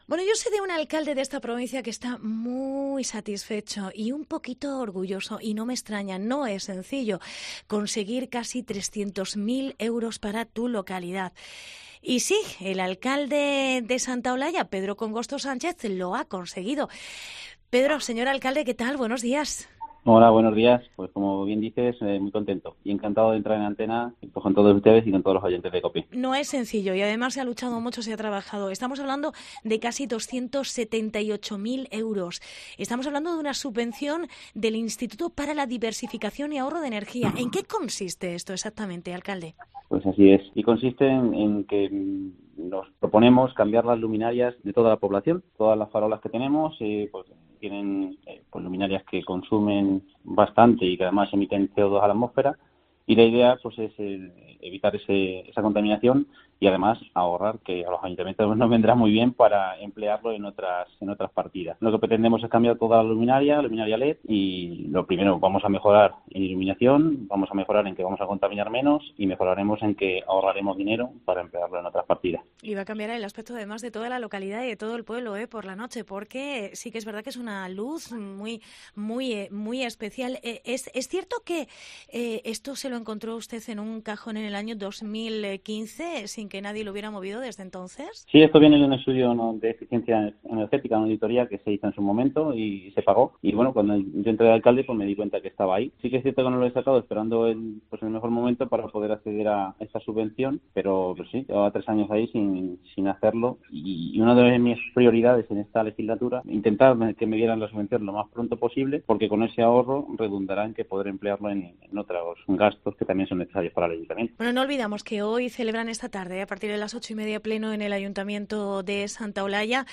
Entrevista Pedro Congosto, alcalde de Santa Olalla